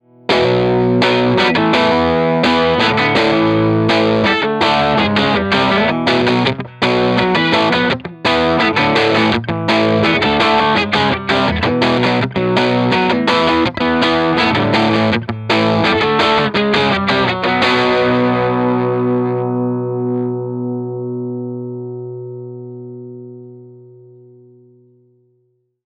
18 Watt v6 - EL84 Dirty Tone Tubby Ceramic
Note: We recorded dirty 18W tones using both the EL84 and 6V6 output tubes.
18W_DIRTY_EL84_ToneTubbyCeramic.mp3